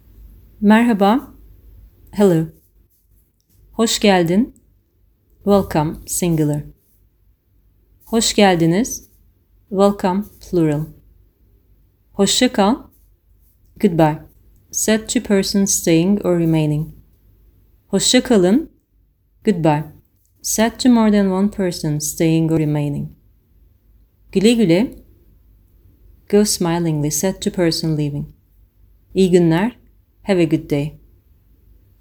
To help avoid confusion, I have italicised the Irish words and phrases, and I’ve also included some Turkish audio clips so you can hear what the language sounds like!
Turkish Greetings
Turkish-Greetings.mp3